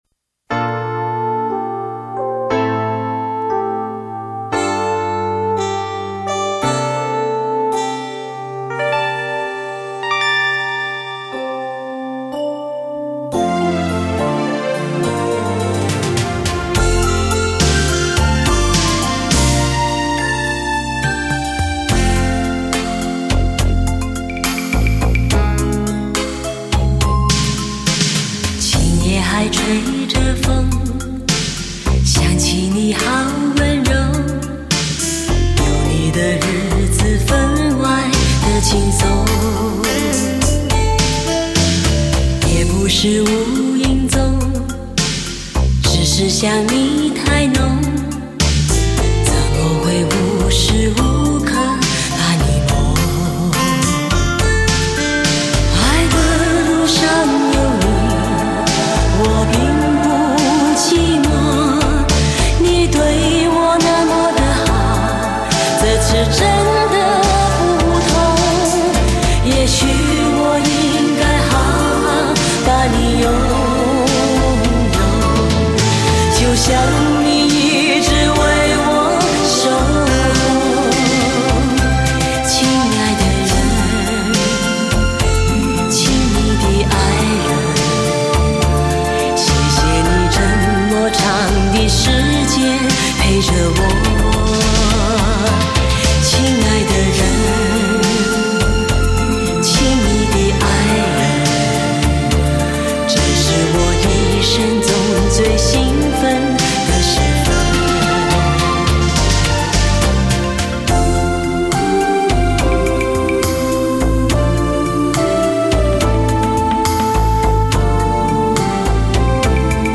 无损音质原人原唱，经典！值得聆听永久珍藏